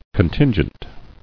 [con·tin·gent]